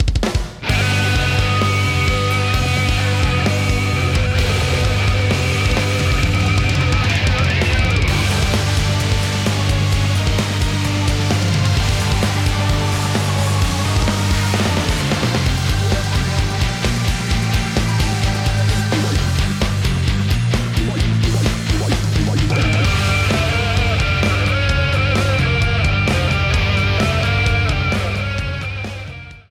A song
Ripped from the game
trimmed to 29.5 seconds and faded out the last two seconds